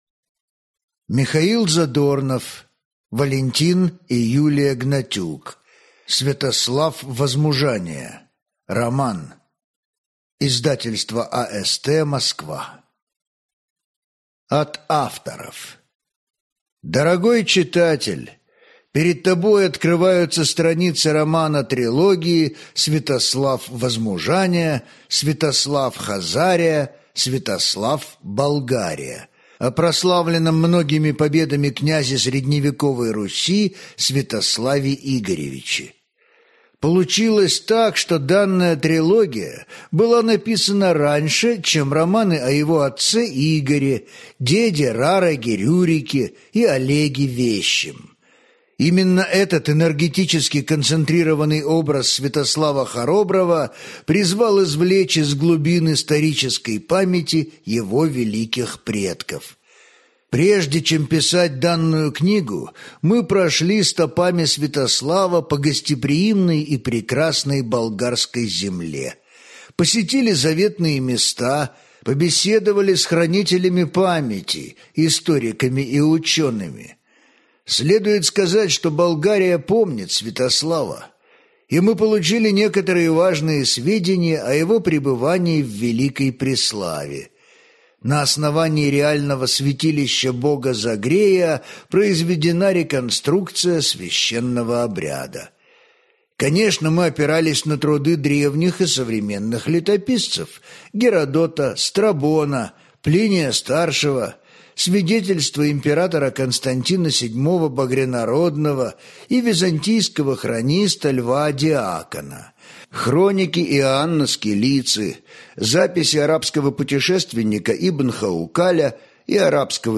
Аудиокнига Святослав. Возмужание | Библиотека аудиокниг